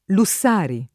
Lussari [ lu SS# ri ] top. (Friuli)